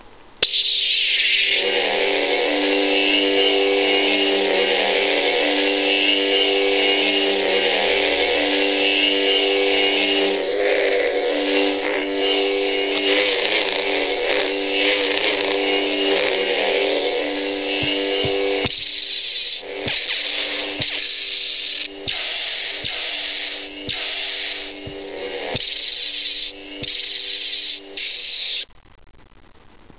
アバウトに音種を解説：起動＝光刃が出る音。駆動＝ブ〜ンというハム音。移動＝振った時に鳴るヴォ〜ンという音。衝撃＝刃をぶつけた時に鳴るバシ〜ンという音。収縮＝光刃が消える音。